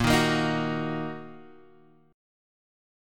A#sus4#5 chord